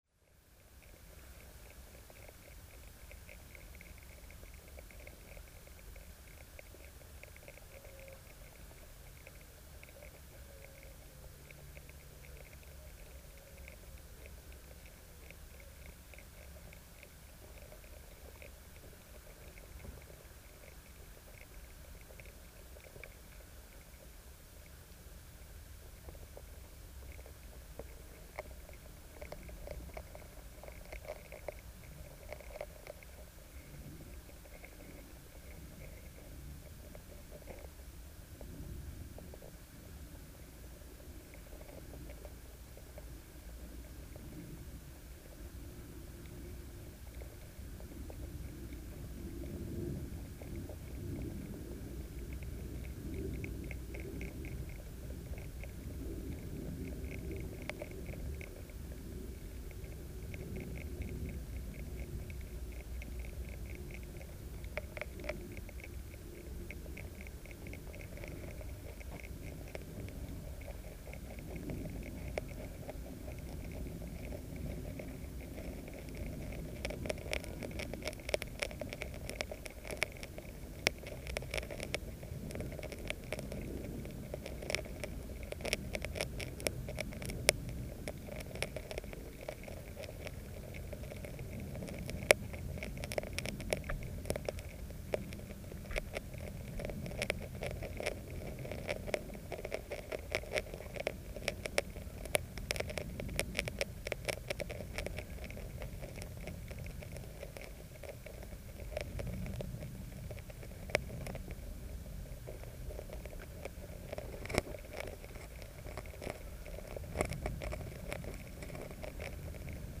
freshwater hydrophone recording using this with an air cell piezo disk hydrophone. At the start of the recording you can faintly hear woodpigeons calling above the surface propagating through the water, and aircraft noise, before the chomping sound